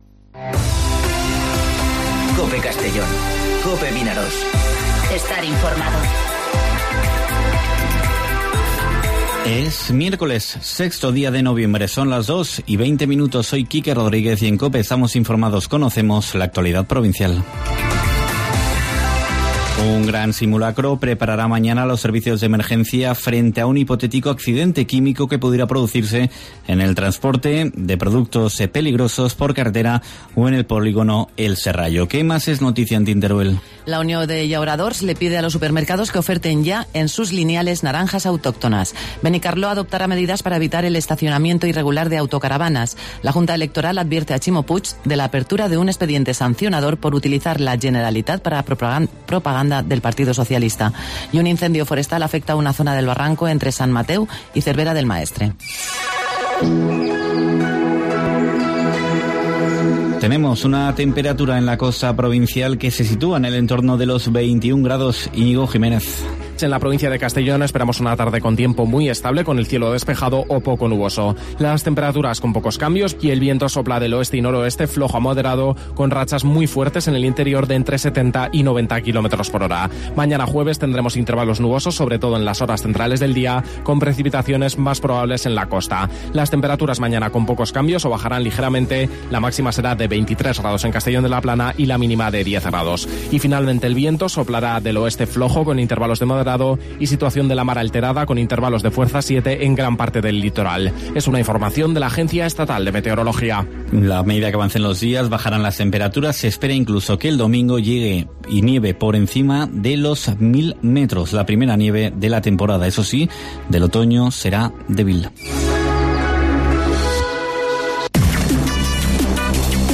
Informativo Mediodía COPE en Castellón (06/11/2019)